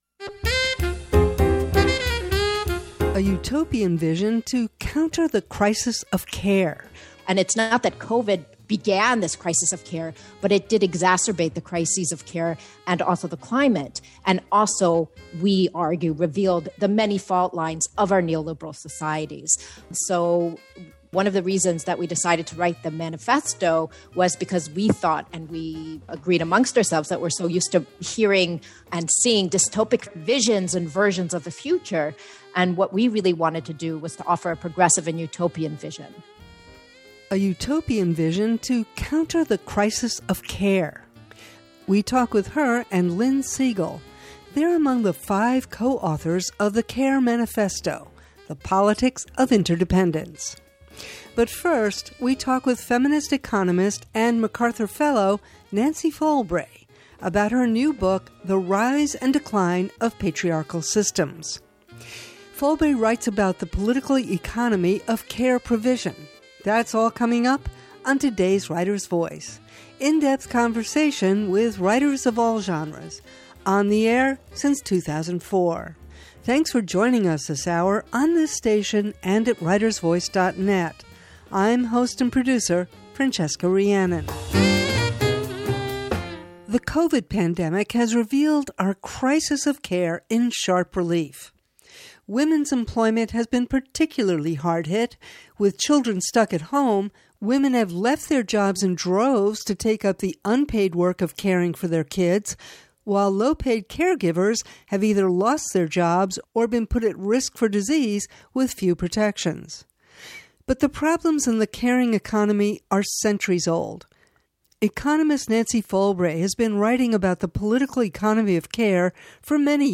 We talk with feminist economist and MacArthur Fellow Nancy Folbre about her new book, The Rise and Decline of Patriarchal Systems. She writes about the political economy of care provision, including at her blog Care Talk.